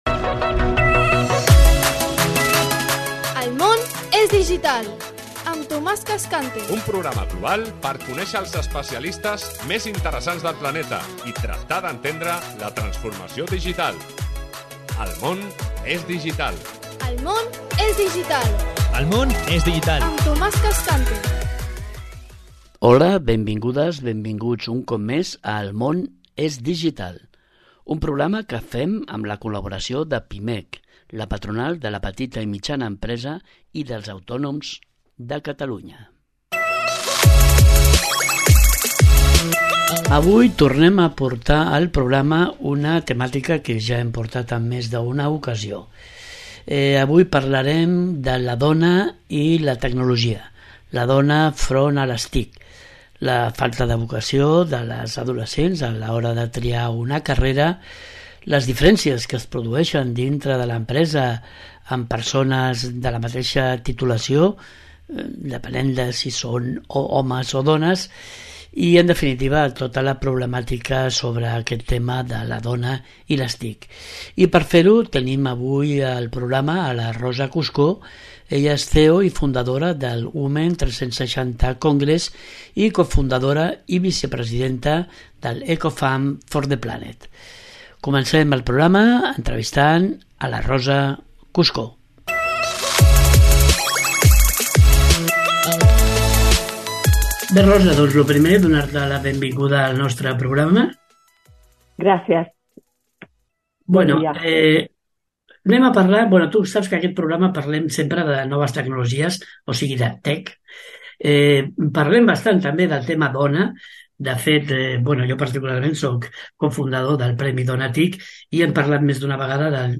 Cada setmana ens apropem a aquesta nova realitat a través de les entrevistes i les tertúlies amb destacats especialistes, directius, emprenedors i usuaris de les noves tecnologies.